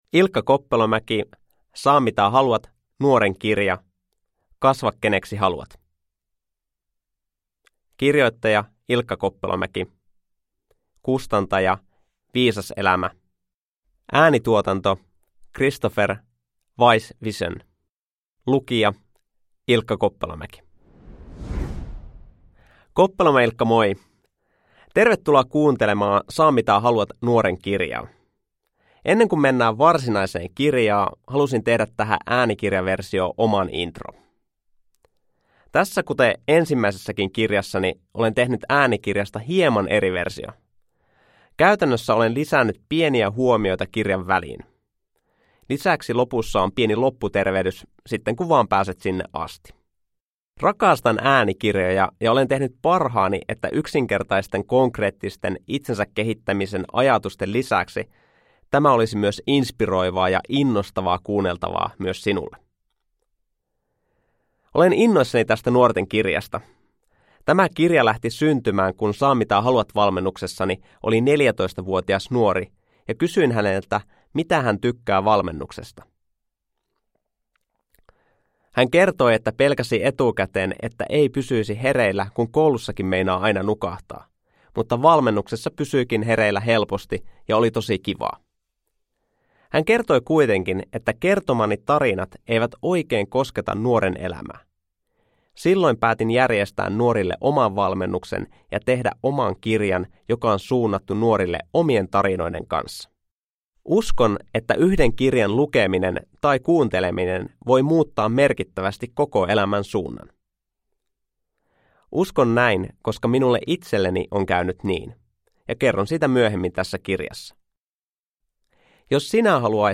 Saa mitä haluat: Nuoren kirja – Ljudbok – Laddas ner